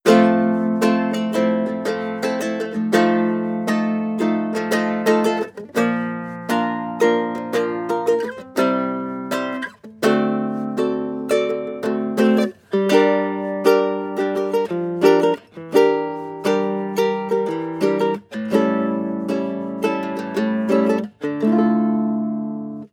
• guitarlele strumming sequence.wav
guitarlele_strumming_sequence_H9P.wav